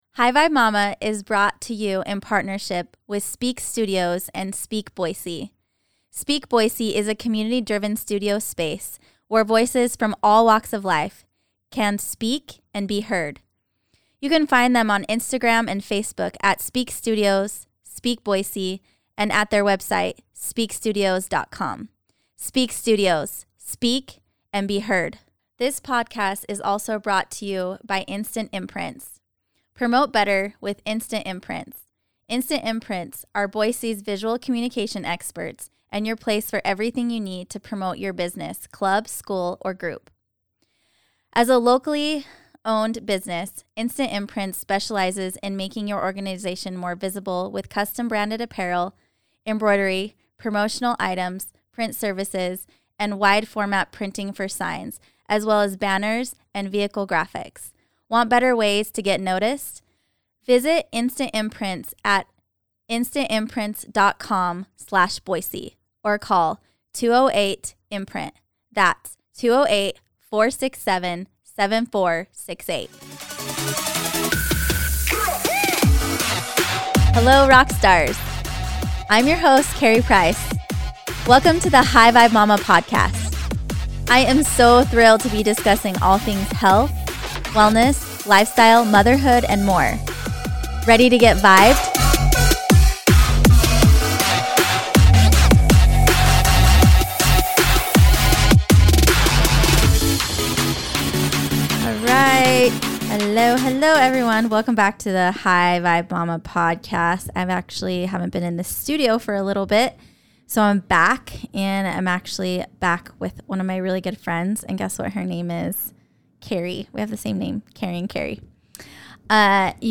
#15 METABOLIC HEALTH , INTERVIEW